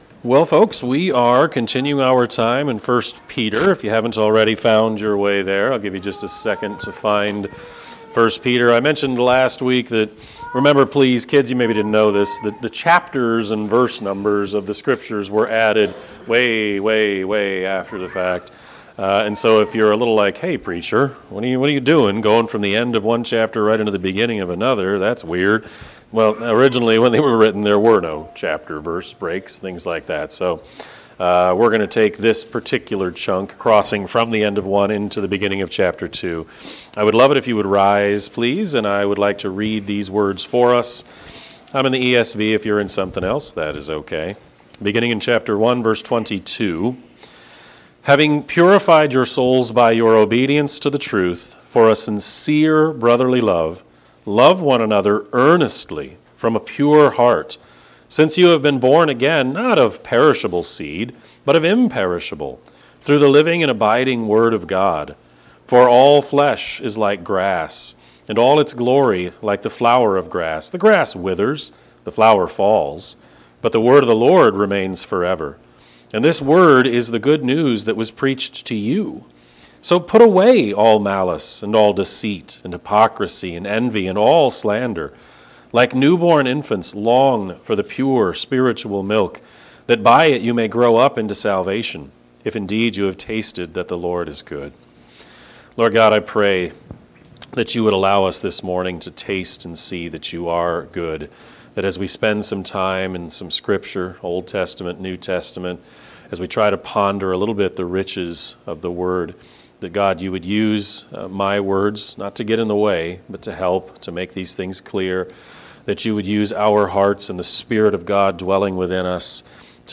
Sermon-4-19-26-Edit.mp3